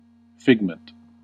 Ääntäminen
Synonyymit langue d'oc Ääntäminen France: IPA: [ʁɔ.mɑ̃] Haettu sana löytyi näillä lähdekielillä: ranska Käännös Konteksti Ääninäyte Substantiivit 1. novel US 2.